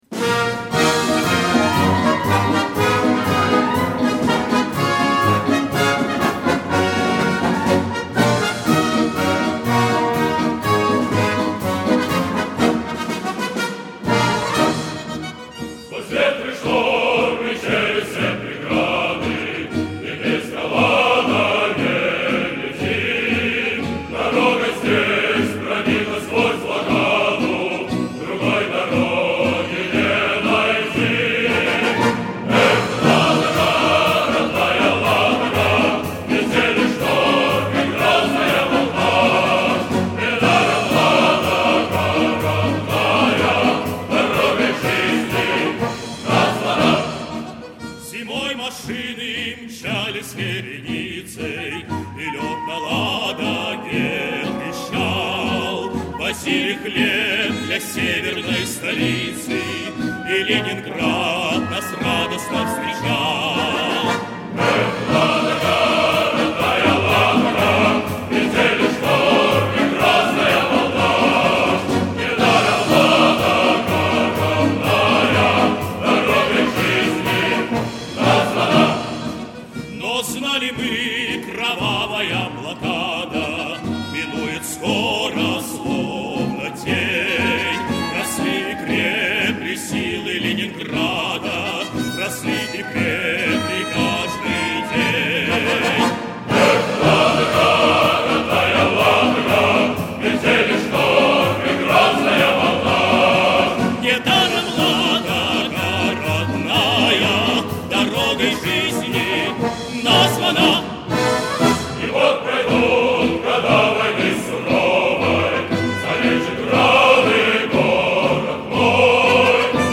Исполнение двух перспективных солистов Ансамбля